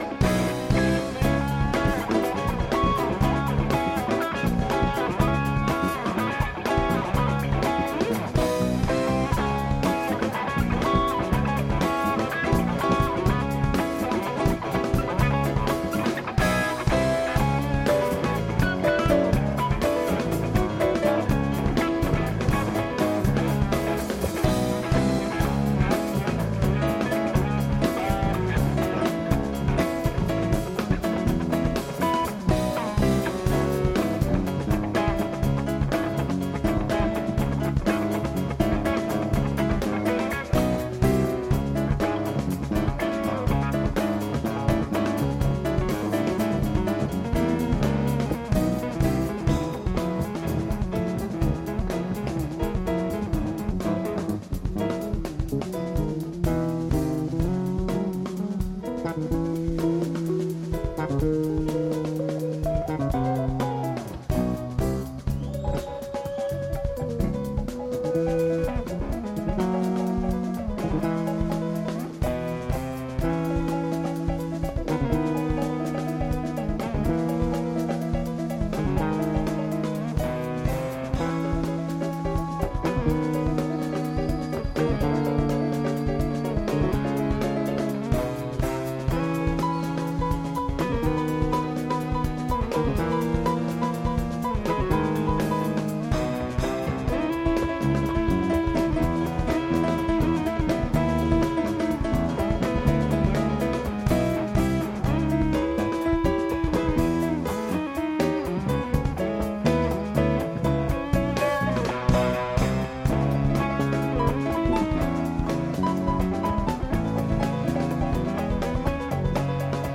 East Gym, Humboldt State University — Arcata, CA 03.28.1993